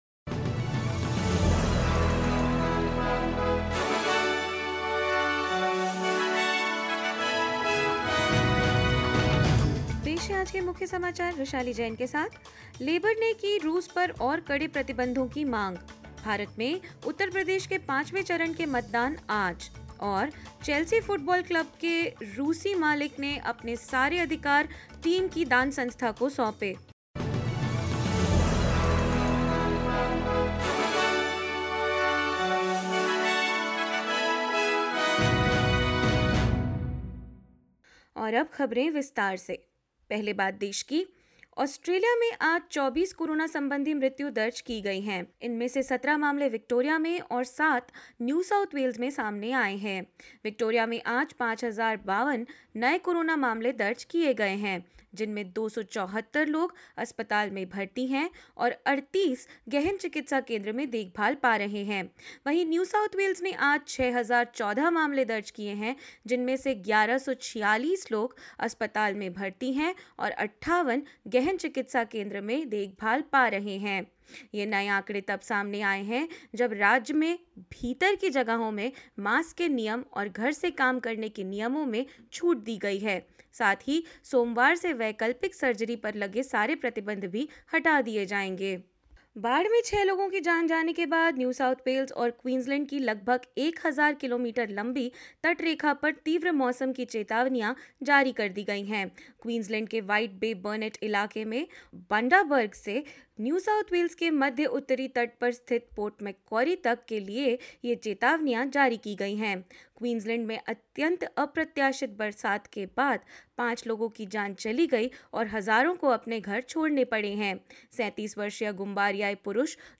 hindi_news_2702.mp3